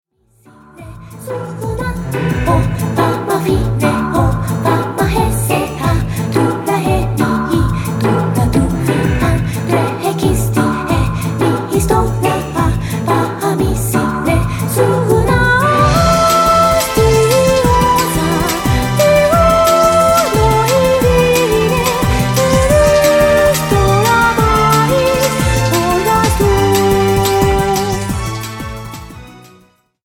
民族音楽調を中心に、様々な方向性を持った4つのボーカル・コーラス曲を収録しました。
ジャンル： World, EasyListening, NewAge